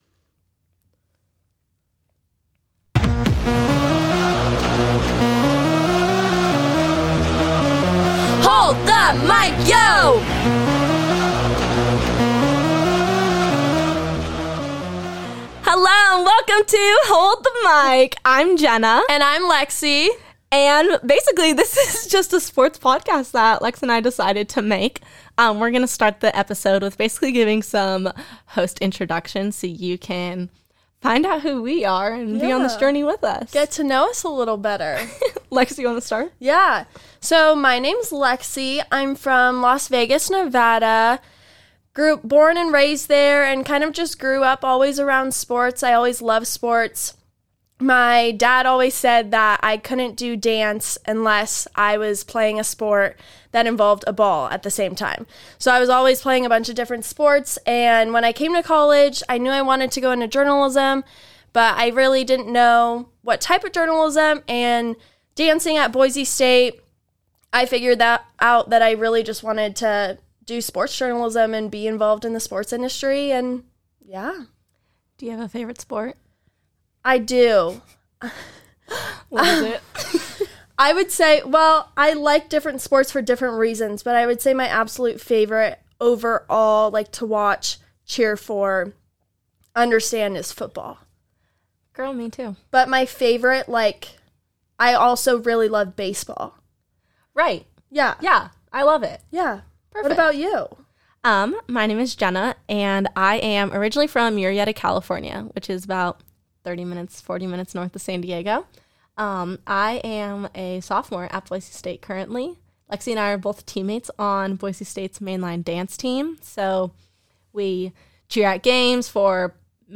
Each week, the podcast will begin with a few segments about current sports news, games happening that week, and then interview someone prevalent in the sports industry. These interviews include coaches, athletes, and reporters at all levels